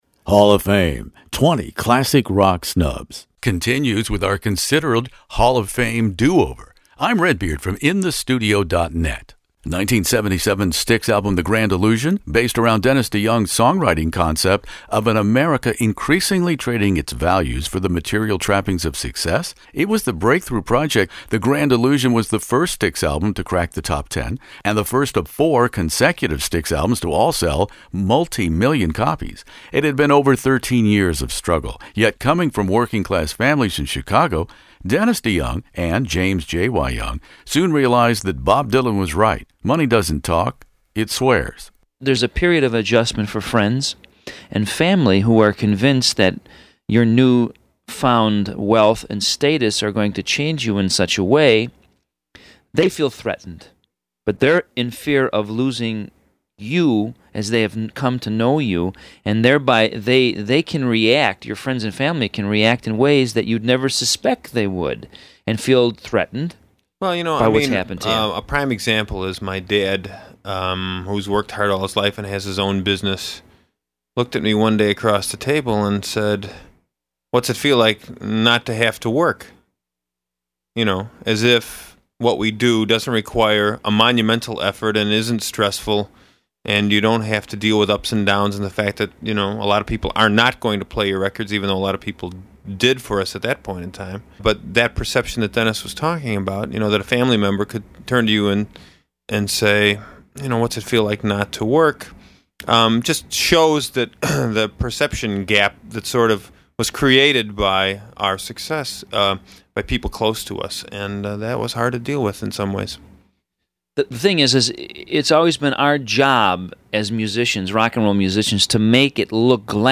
The release of the impressive premium vinyl deluxe box set The A&M Albums 1975-1984   gave current Styx mainstays Tommy Shaw, James “JY” Young, and Lawrence Gowan a wonderful opportunity to document the musical bookends of that period including 1975’s first on the label, Equinox,  followed by Shaw’s debut on Crystal Ball   in 1976, while ex-member and Styx co-founder Dennis DeYoung honestly and eloquently reveals triumphs and missteps along the way in this classic rock interview.